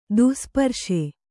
♪ duh sparśe